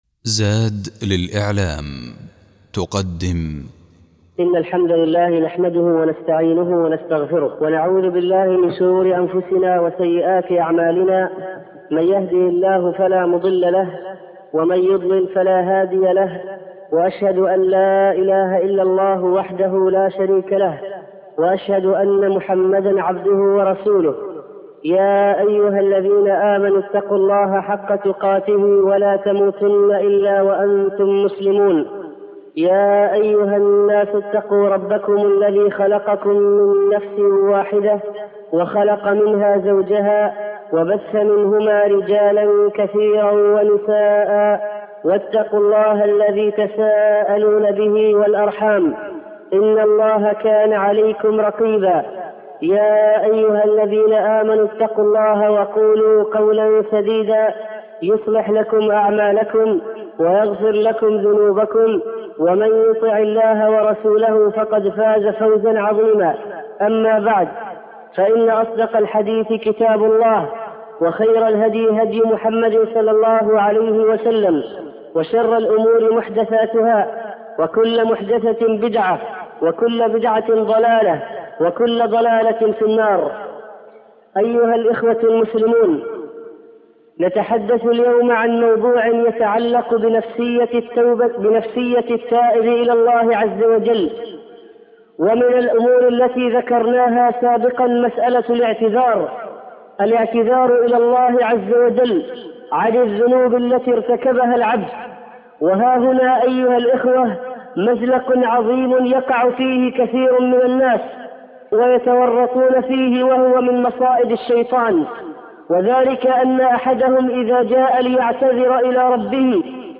الخطب